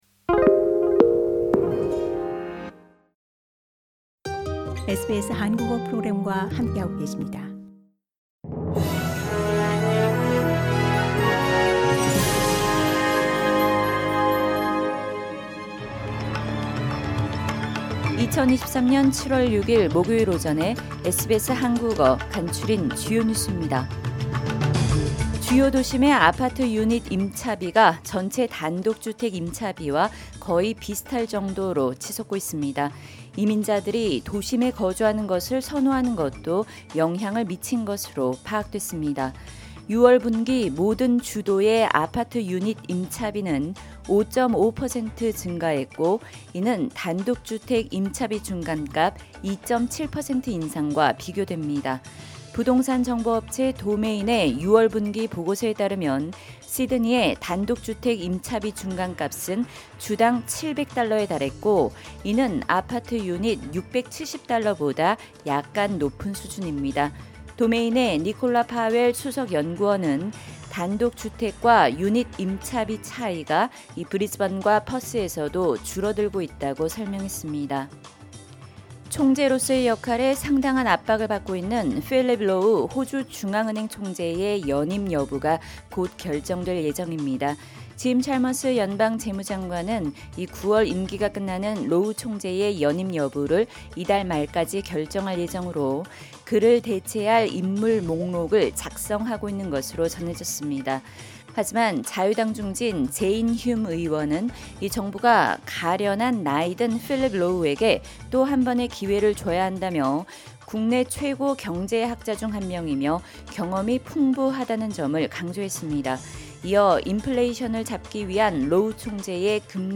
SBS 한국어 아침 뉴스: 2023년 7월 6일 목요일